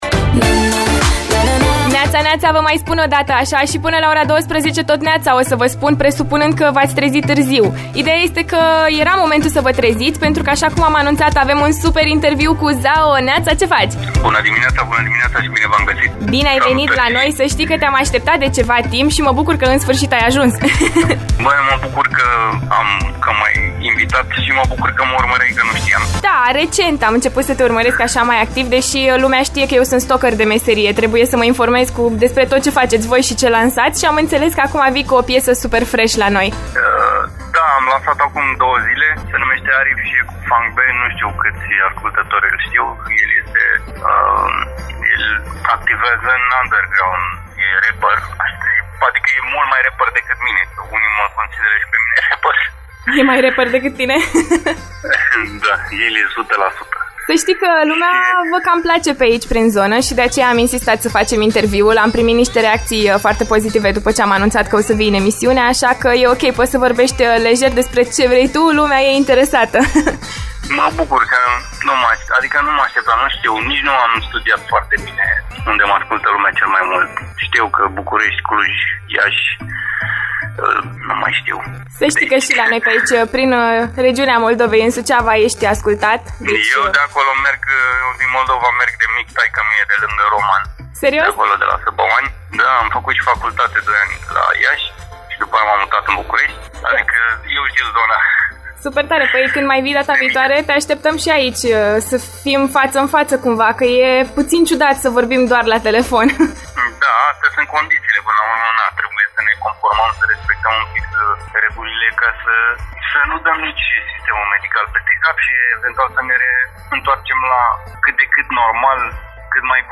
interviu LIVE la After Morning